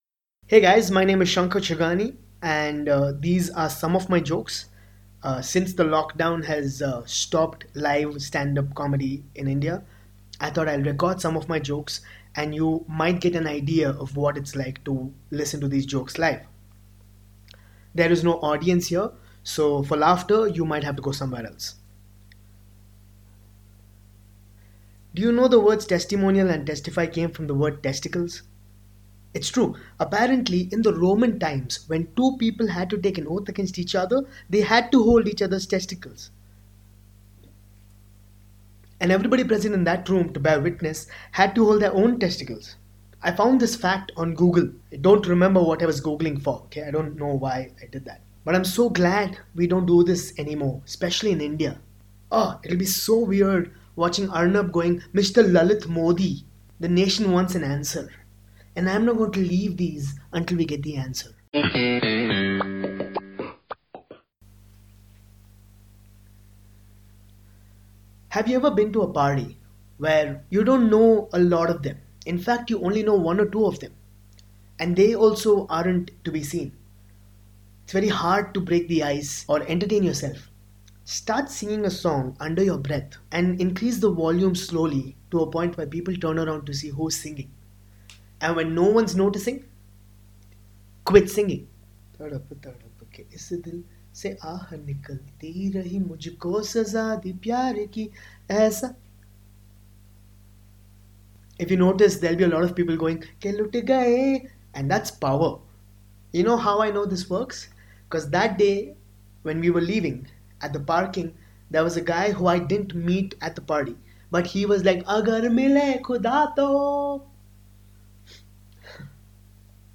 Isolated Stand-Up Set